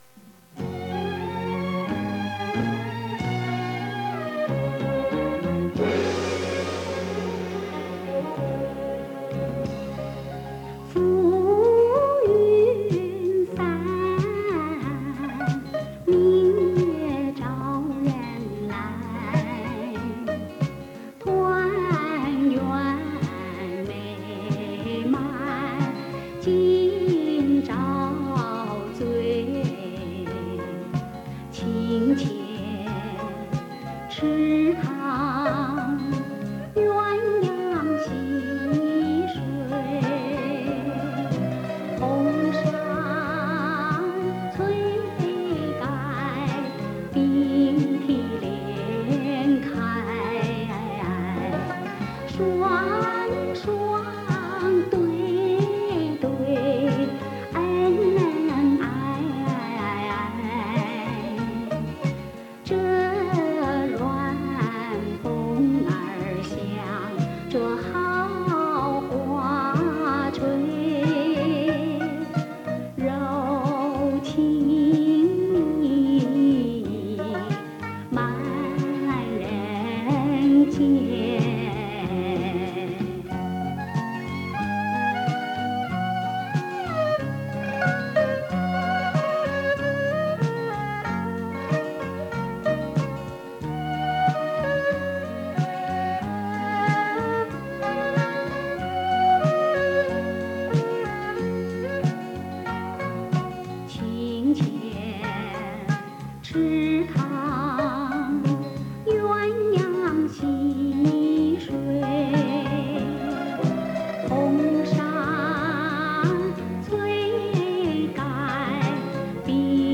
重新录制 原音重现
试听音质不错，感谢制作上传。